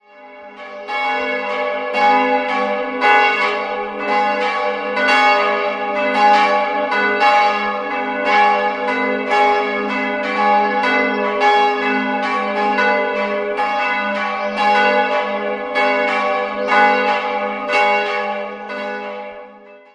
Die große Glocke wurde von Friedrich Wilhelm Schilling gegossen, eine weitere stammt aus dem Jahr 1723 von Johann Straßer aus Regensburg. Über die dritte, ebenfalls historische Glocke, liegen keine genauen Informationen vor.